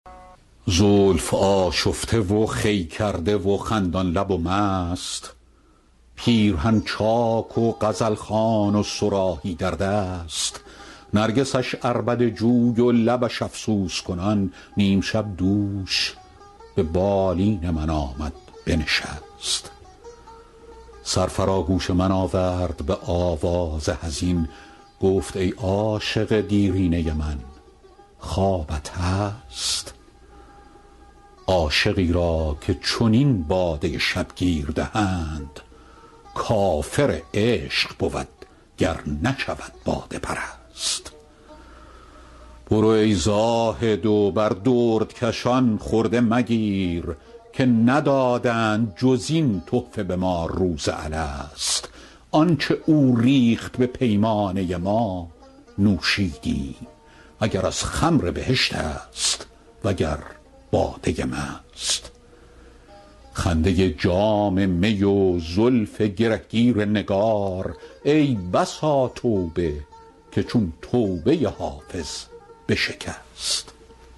حافظ غزلیات غزل شمارهٔ ۲۶ به خوانش فریدون فرح‌اندوز